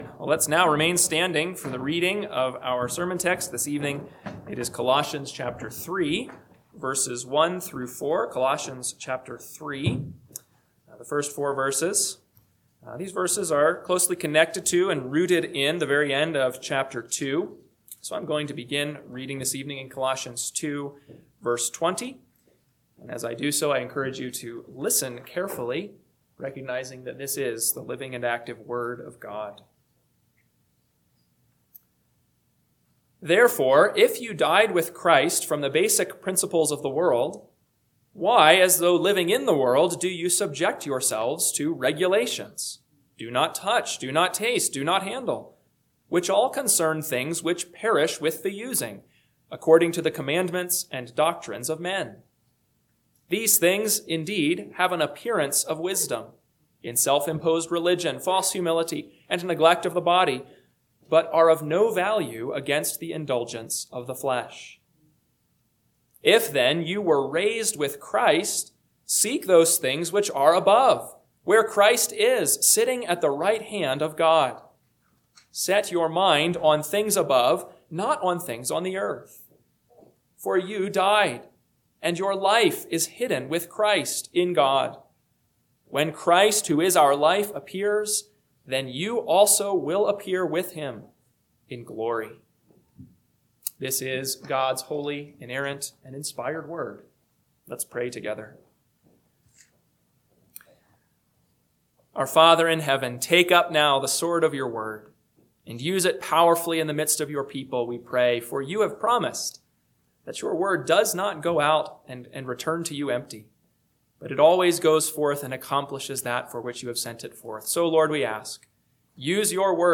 PM Sermon – 3/22/2026 – Colossians 3:1-4 – Northwoods Sermons